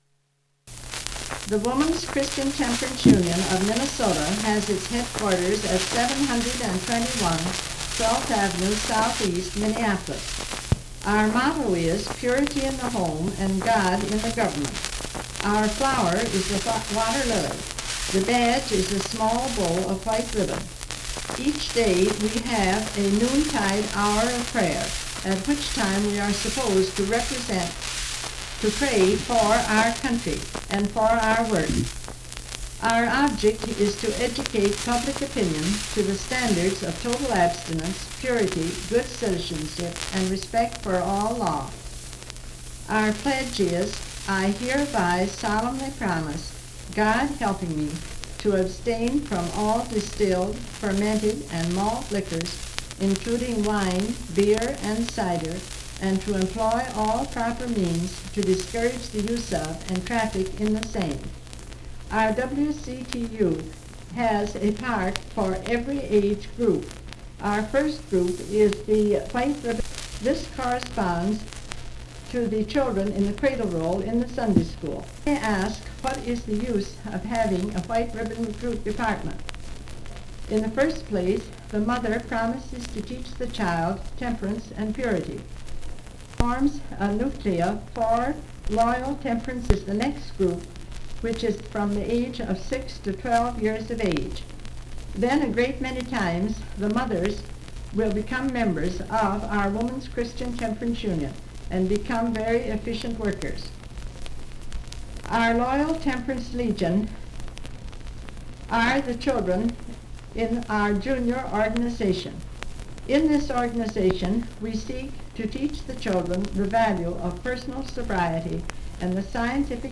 Sound quality impacted by hiss and pops
Container note: Recording discs made by Wilcox-Gay.
78 rpm; 10 inches and 1 user audio file